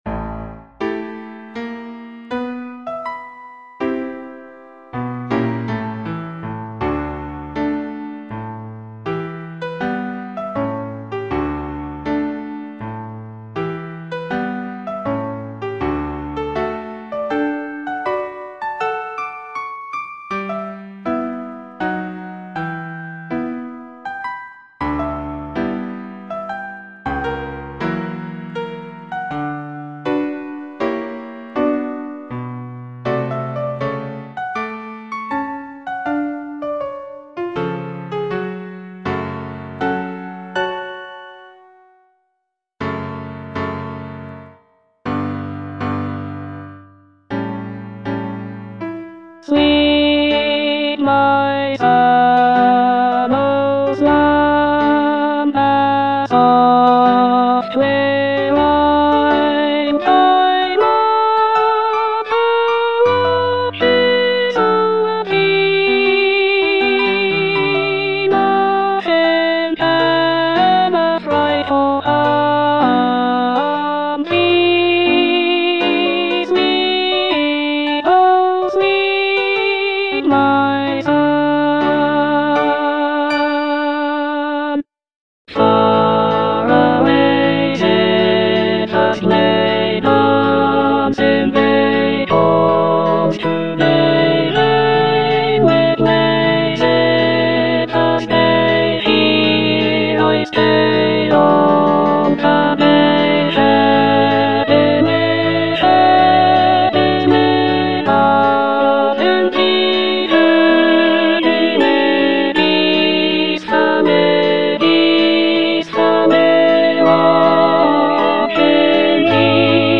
E. ELGAR - FROM THE BAVARIAN HIGHLANDS Lullaby (alto II) (Emphasised voice and other voices) Ads stop: auto-stop